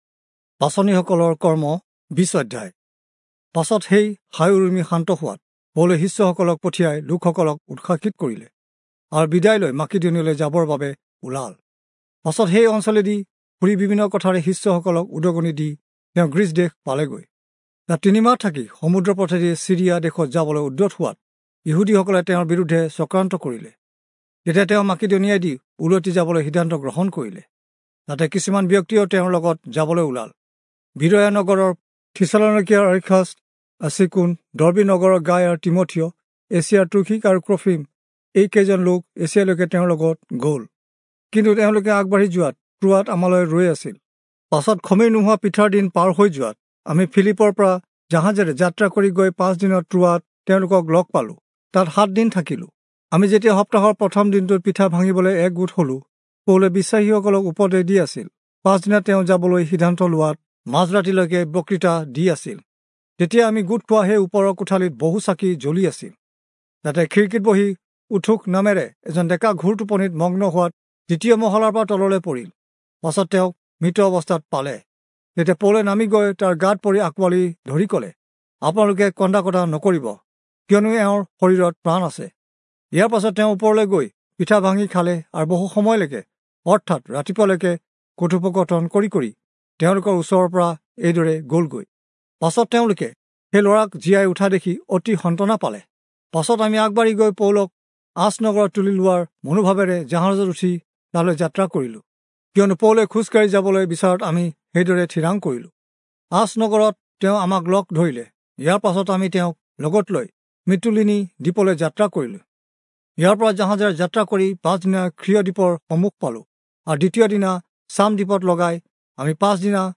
Assamese Audio Bible - Acts 25 in Hov bible version